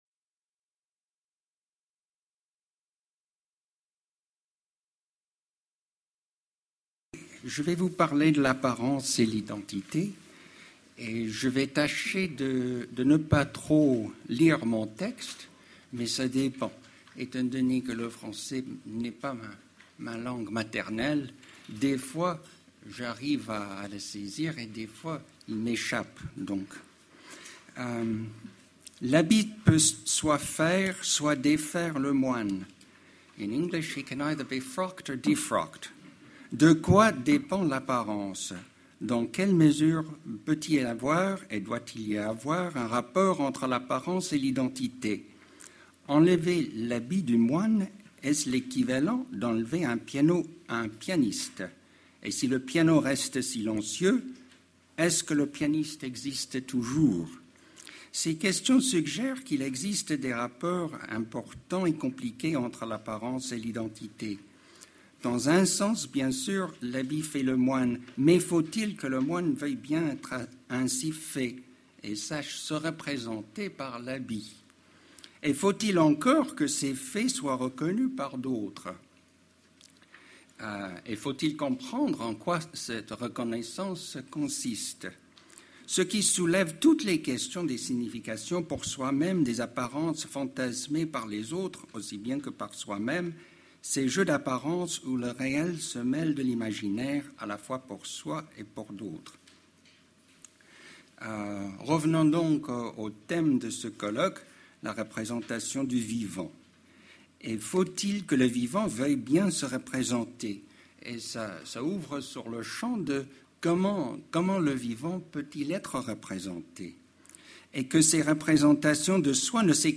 La conférence est suivie de questions du public sur l'identité, l'image de soi.